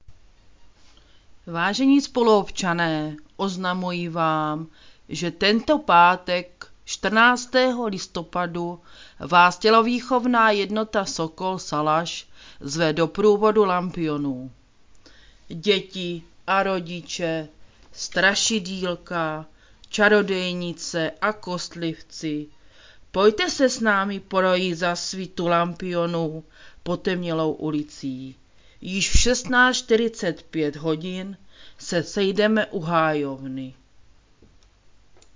Hlášení místního rozhlasu
Samotné hlášení provádí pracovníci kanceláří místních částí ze svých pracovišť.